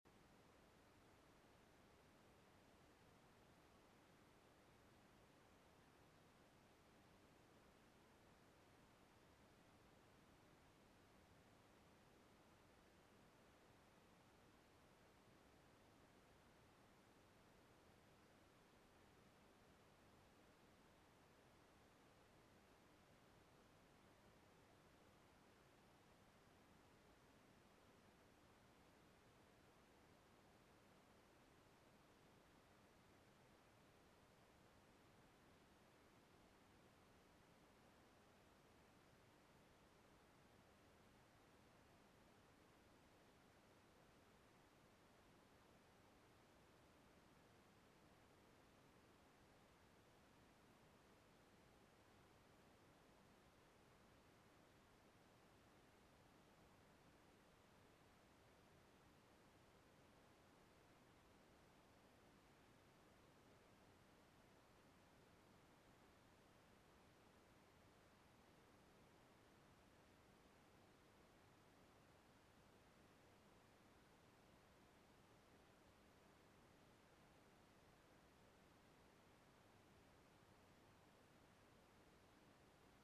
Broadcasting live from Catskill, NY.